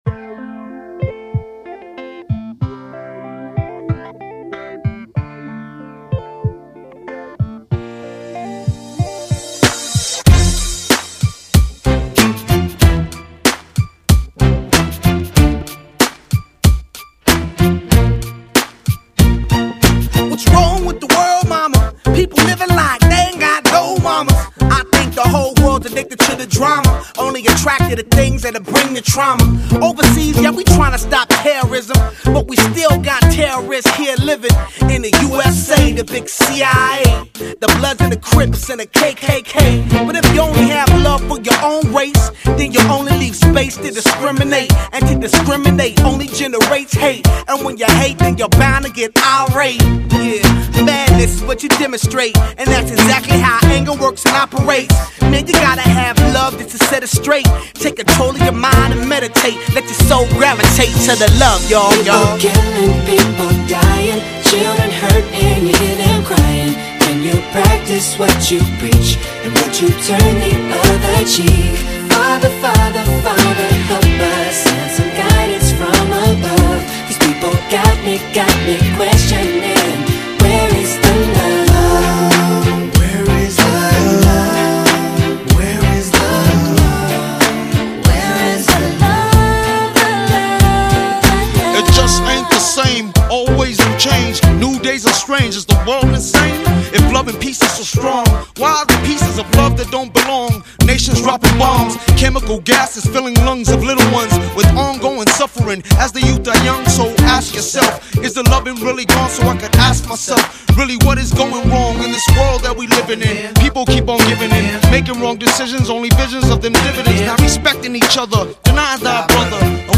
是一支深受灵魂乐、爵士乐与拉丁节奏与现场演唱精神所启发的放克/嘻哈队伍